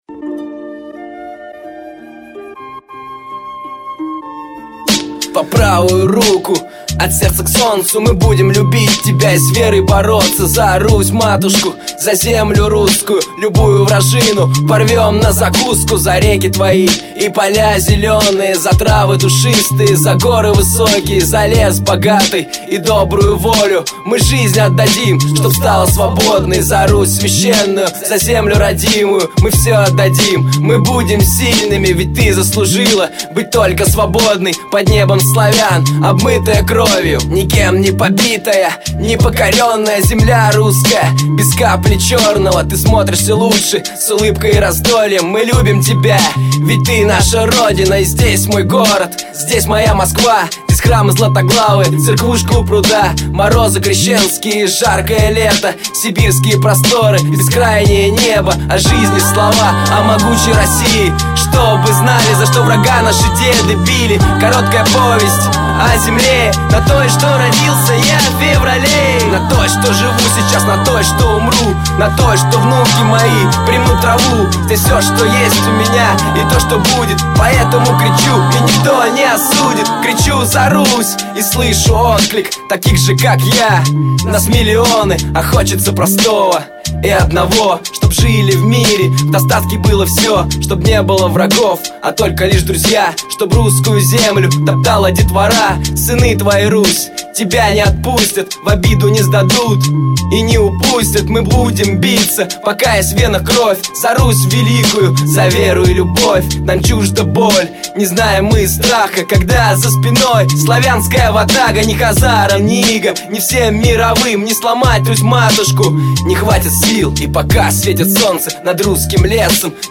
S_patrioticheskij_rep___Za_s_.mp3